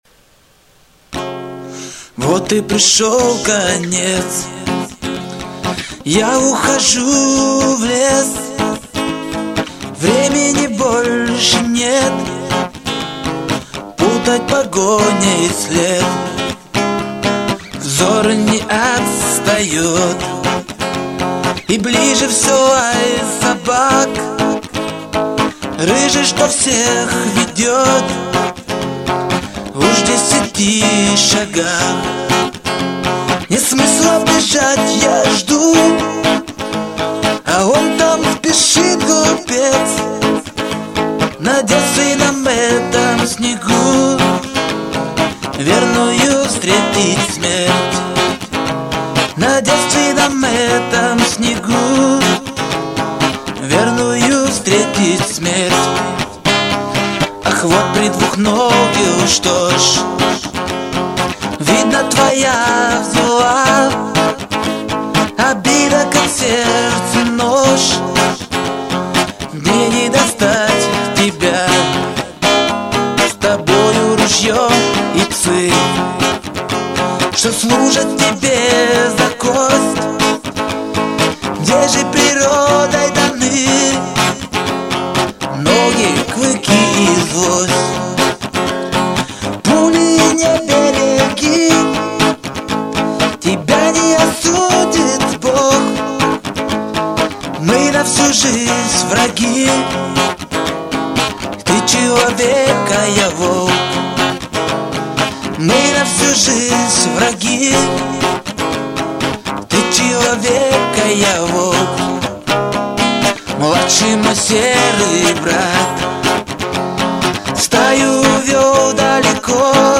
Бой - вниз, вверх заглушка, вверх, вниз.